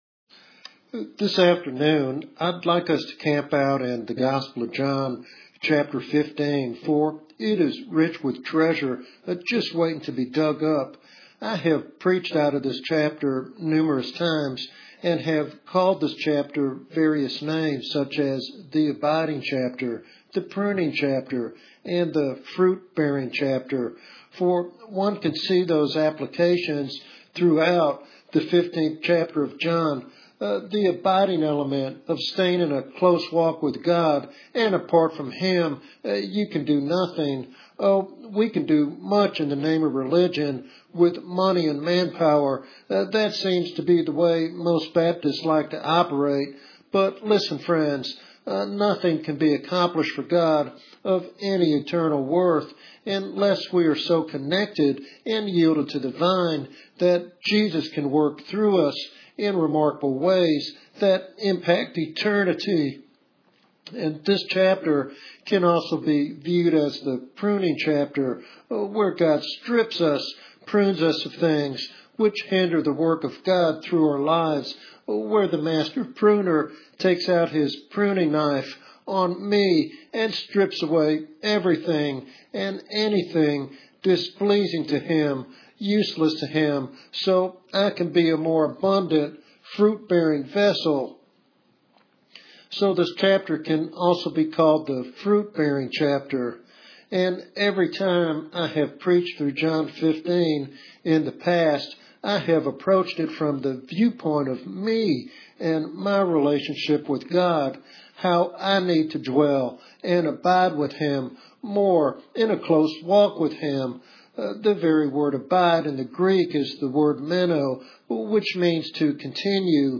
In this expository sermon